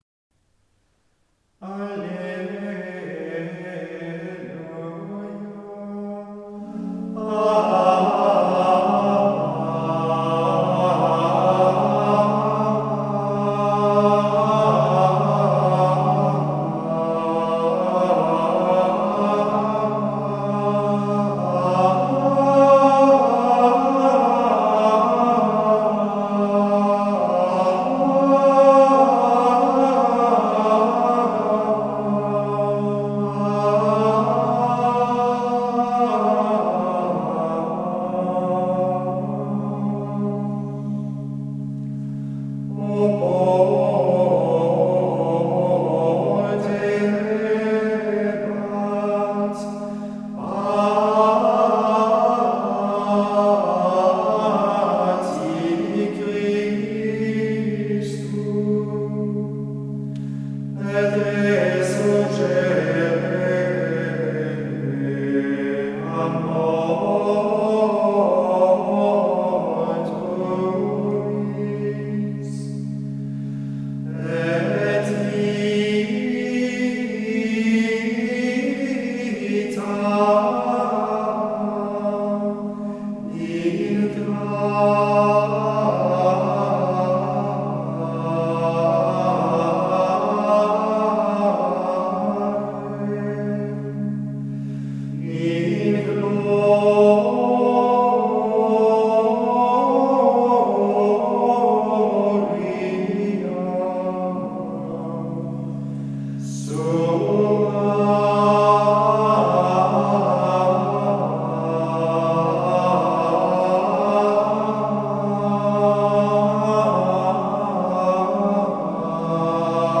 03-alleluja-2-oportebat.mp3